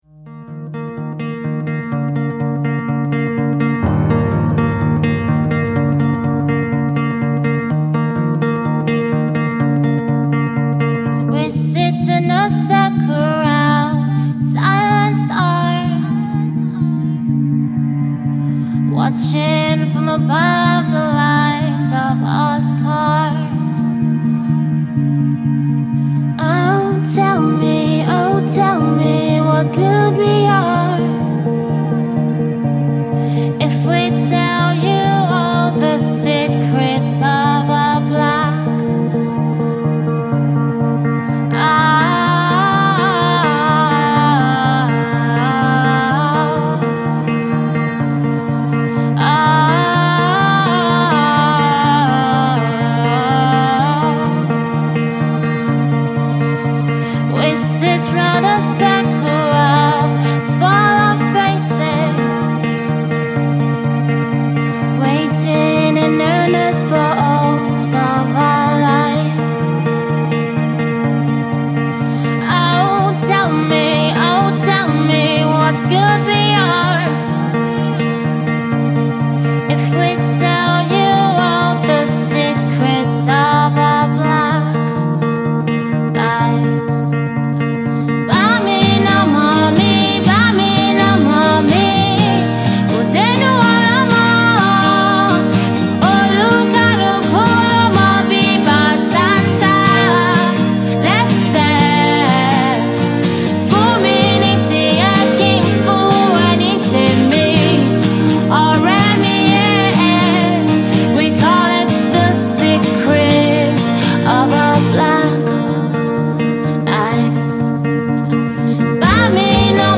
alternative/ indie-folk